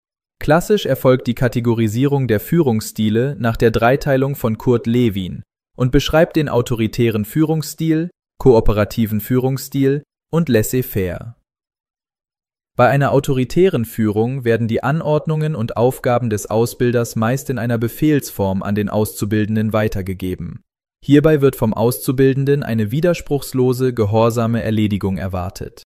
Folge 5 der Podcast-Reihe „Lektion k„, gesprochen vom virtuellen Avatar Lucas Levien.